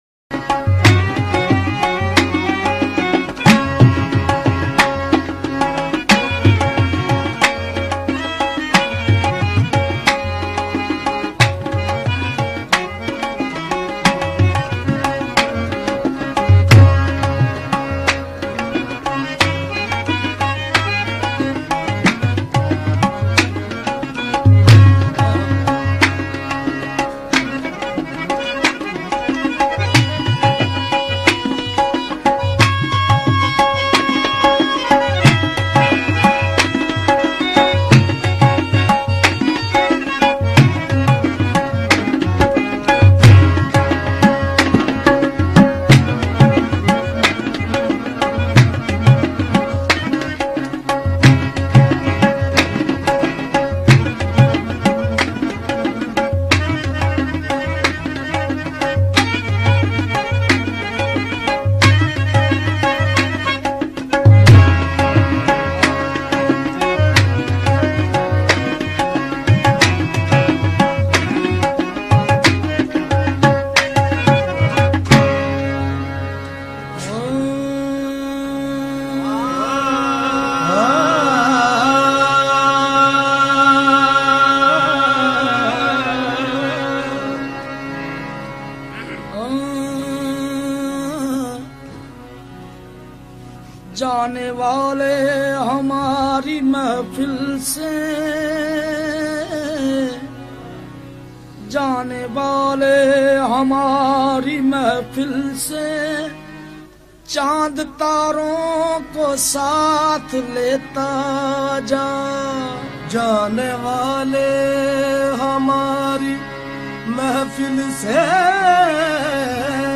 heart-wrenching Urdu love song
Sufi Songs